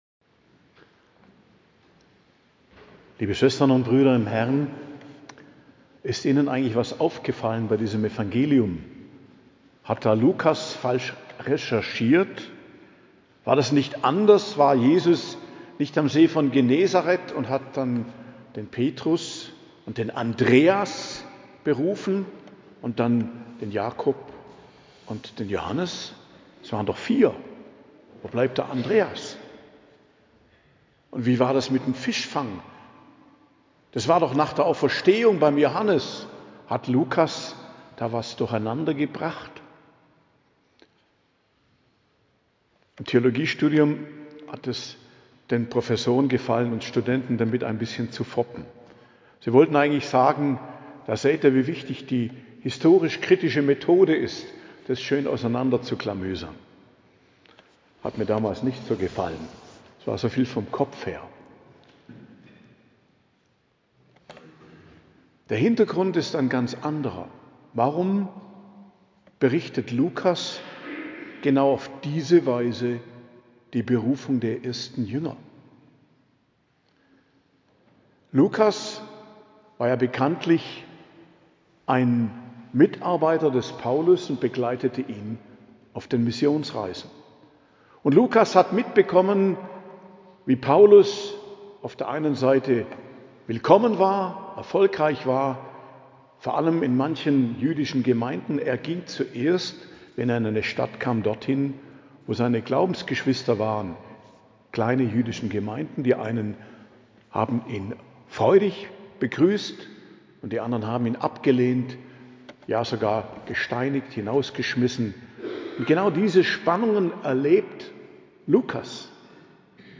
Predigt zum 5. Sonntag i.J. am 9.02.2025 ~ Geistliches Zentrum Kloster Heiligkreuztal Podcast